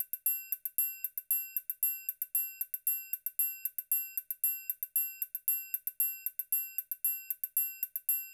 01 Triangle.wav